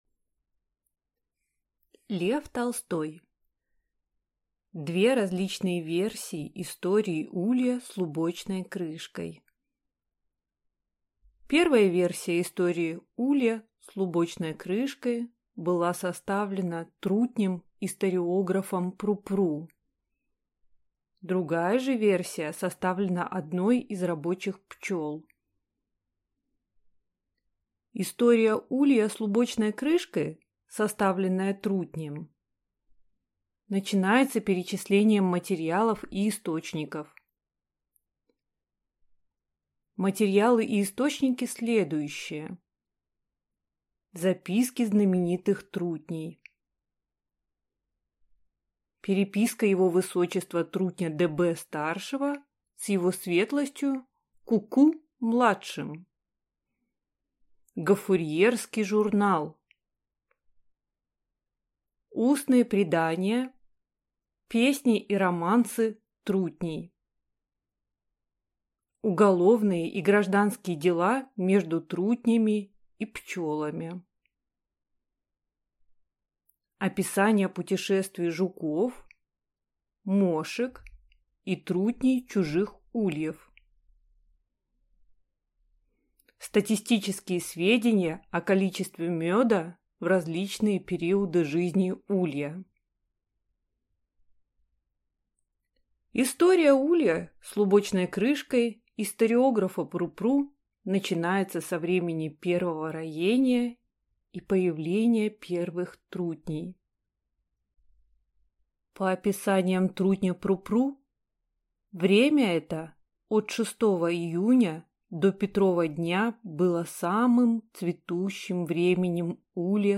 Аудиокнига Две различные версии истории улья с лубочной крышкой | Библиотека аудиокниг